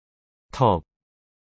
韓国で「あご」は「턱（トク）」と表現されます。